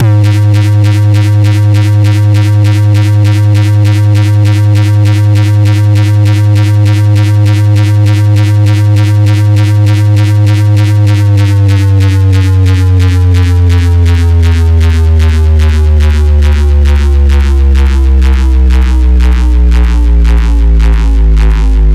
Desecrated bass hit 13.wav